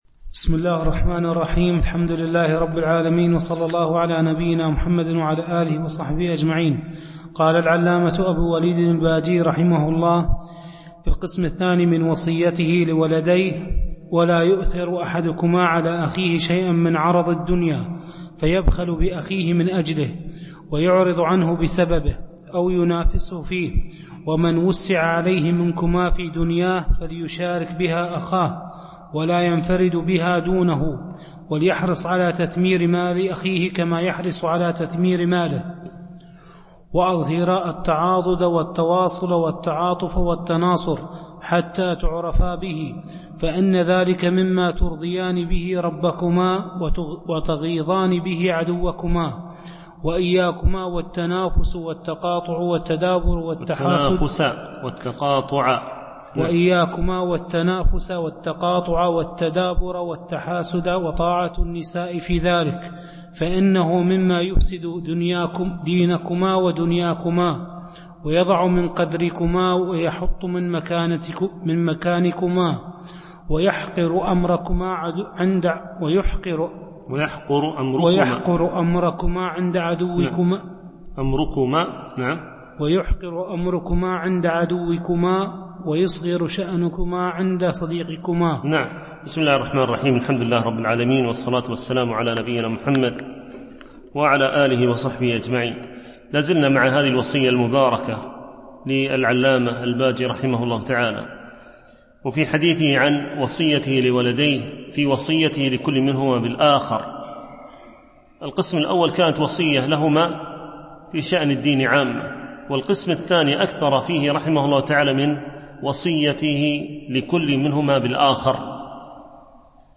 شرح النصيحة الولدية ـ الدرس 15